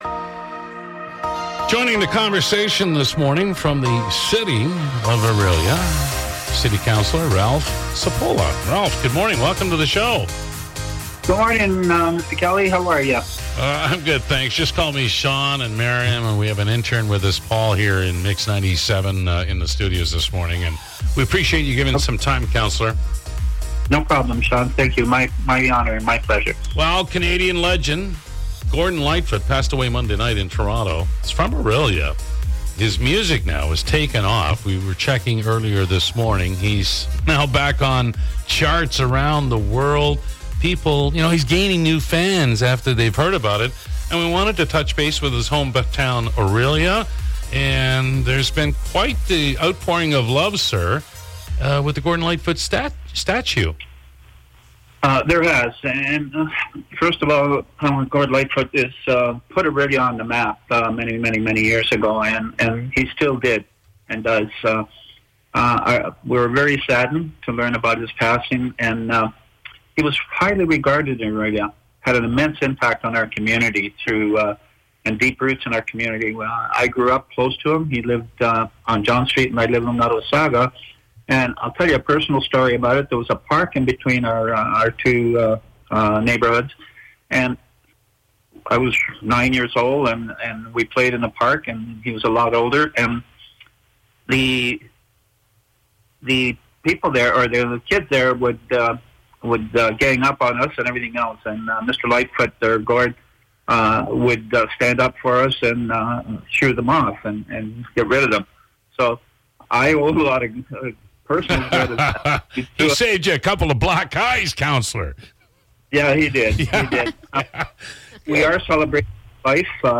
To celebrate Gordon Lightfoot’s life, the MIX Morning Crew chat with Orillia City Councilor Ralph Cipolla